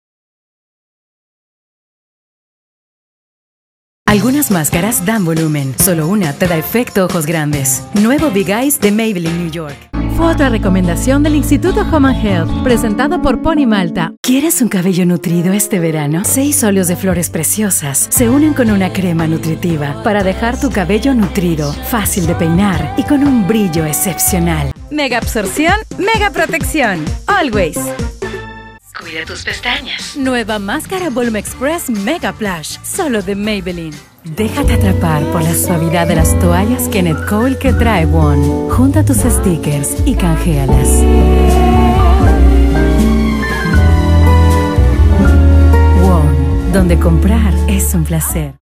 30 years of experience doing character roles, teen and kids voices, young adult and middle age female: IVRs, dubbing, cartoon voices, e-learnings, Apps, Radio, TV programs, etc.
spanisch Südamerika
Sprechprobe: Werbung (Muttersprache):